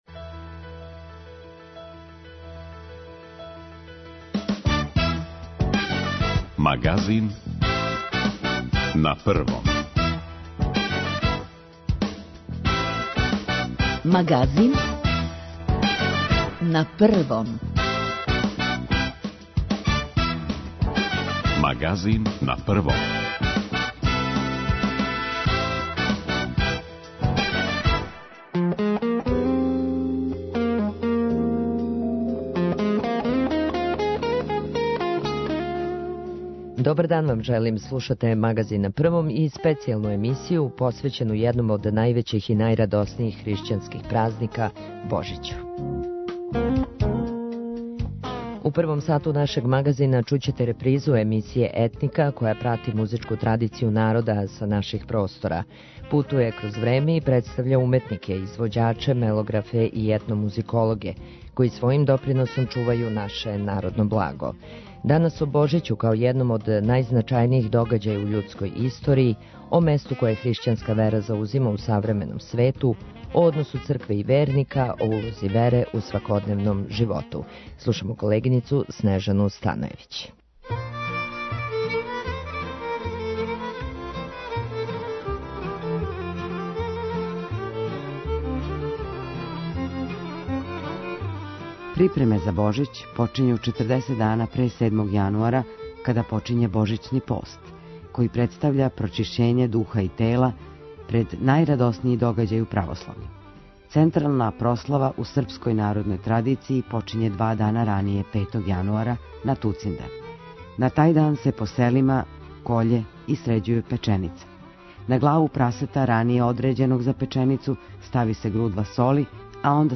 У првом сату нашег Магазина чућете репризу емисије Етника која прати музичку традицију народа са наших простора. Говорићемо о Божићу као једном од најзначајнијих догађаја у људској историји, о односу цркве и верника, улози вере у свакодневном животу...